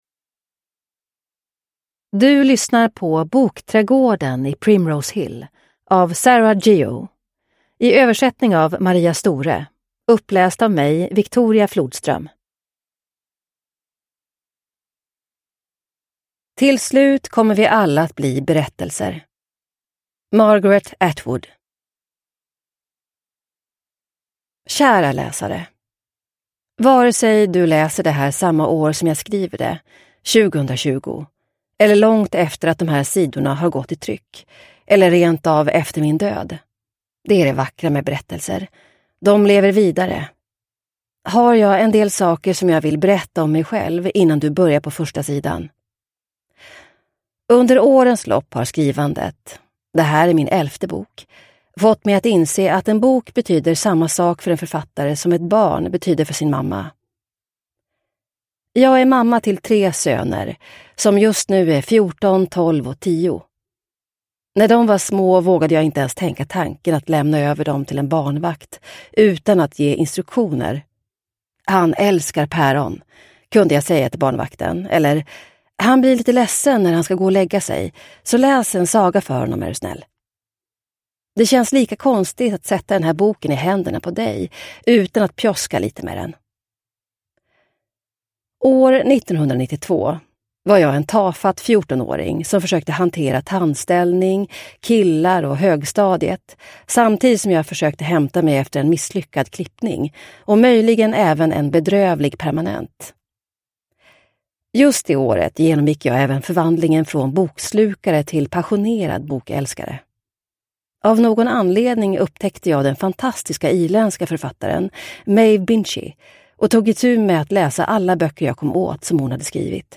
Bokträdgården i Primrose Hill – Ljudbok – Laddas ner